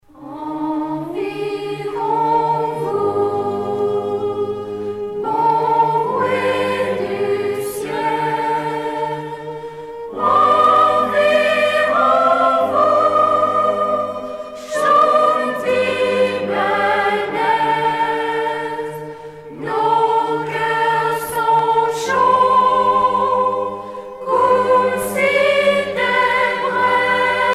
circonstance : Noël, Nativité
Genre strophique
Pièce musicale éditée